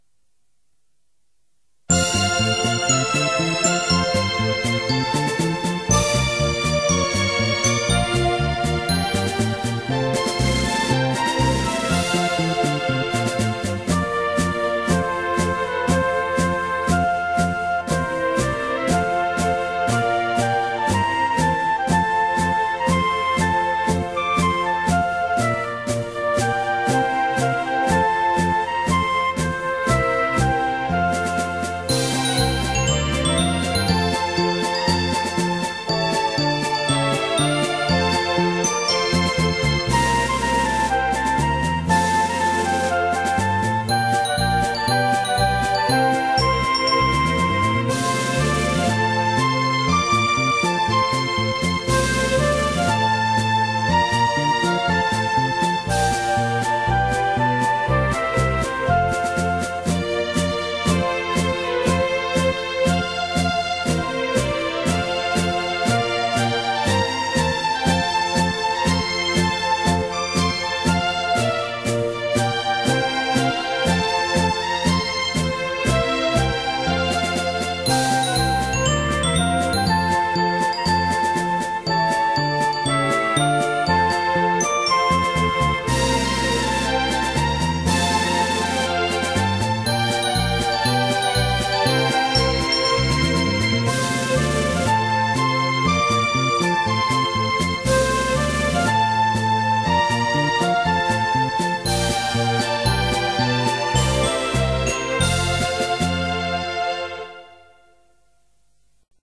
城南中学校 校歌・校章 いいね！